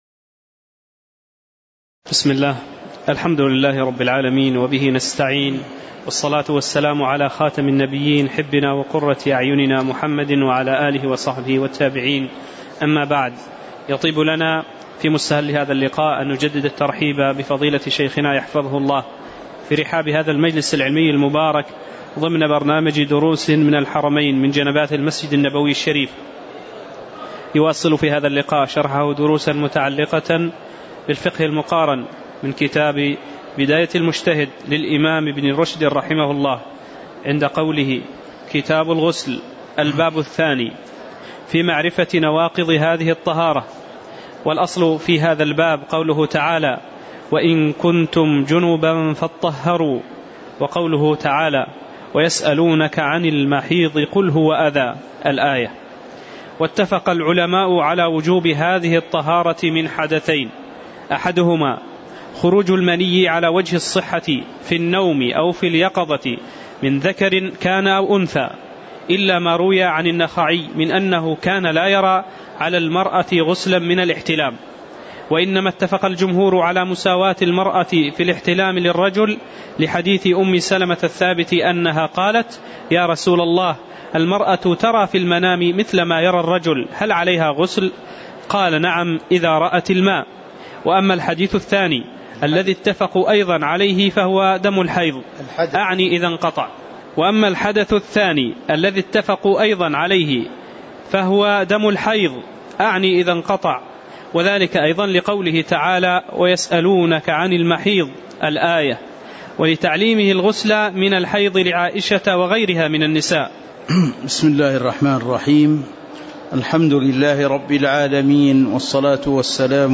تاريخ النشر ١٧ ربيع الأول ١٤٤٠ هـ المكان: المسجد النبوي الشيخ